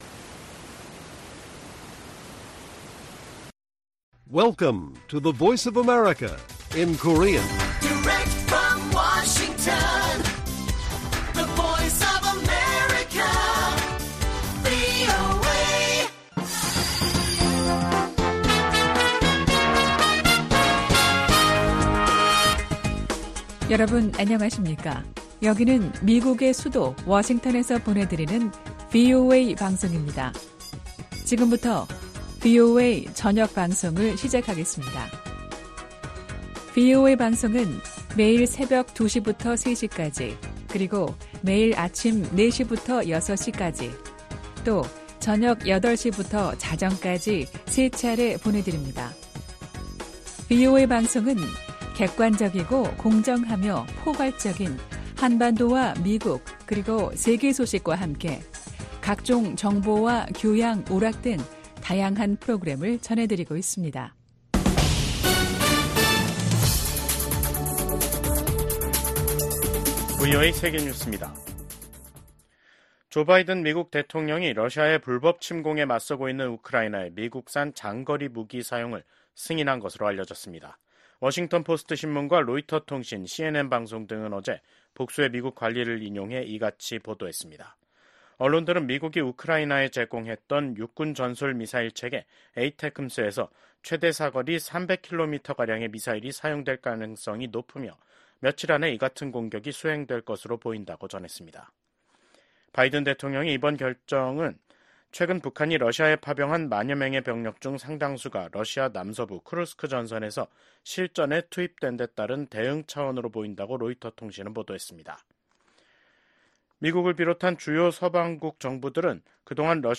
VOA 한국어 간판 뉴스 프로그램 '뉴스 투데이', 2024년 11월 18일 1부 방송입니다. 미국과 한국, 일본의 정상이 북한군의 러시아 파병을 강력히 규탄했습니다. 디미트로 포노마렌코 주한 우크라이나 대사가 VOA와의 단독 인터뷰에서 러시아와 우크라이나 간 격전지인 러시아 쿠르스크에 북한군 장군 7명이 파병됐다고 밝혔습니다.